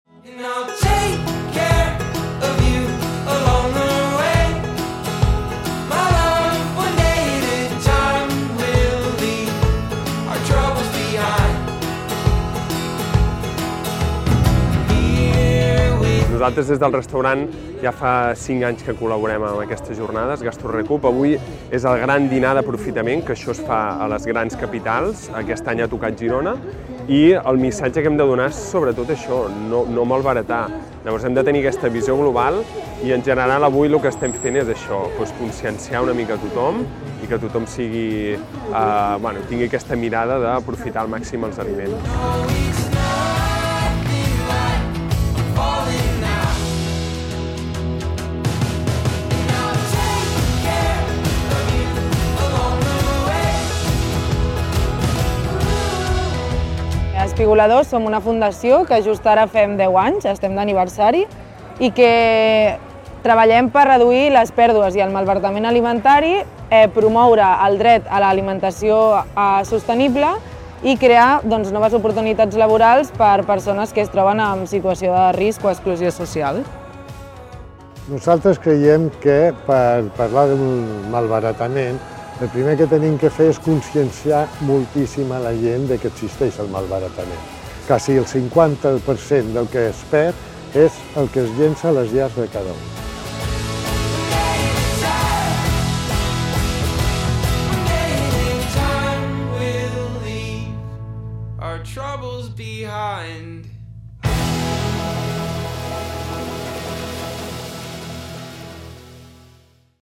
Vídeo de resum del Gran Dinar d'Aprofitament celebrat a Girona el 5 d'octubre del 2024.